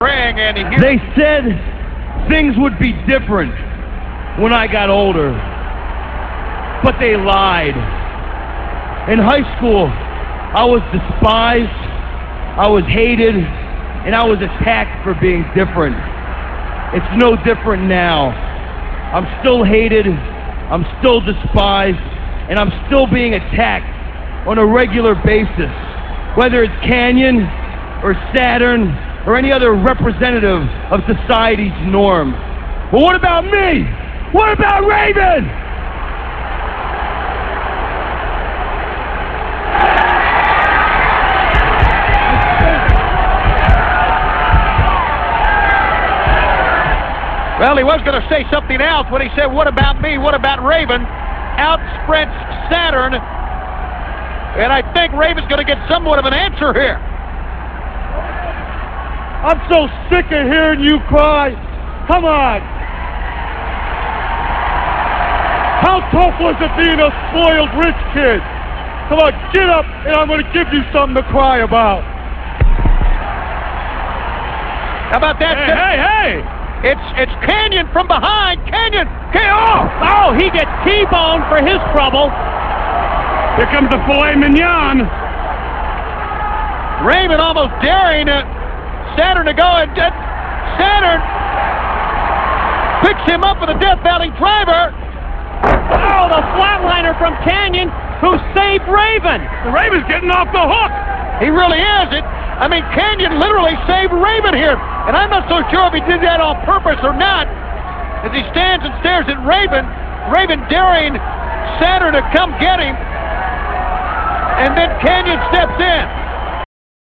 - This speech comes from Nitro - [7.27.98]. Raven talks about how people told him things would change as he got older, but they lied.